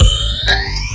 DUBLOOP 05-L.wav